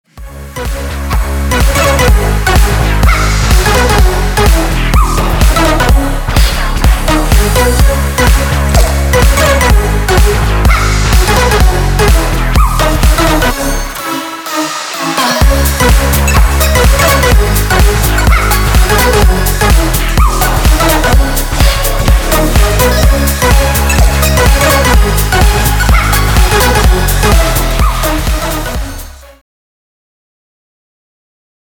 громкие
EDM
без слов
Electronica
Стиль: future house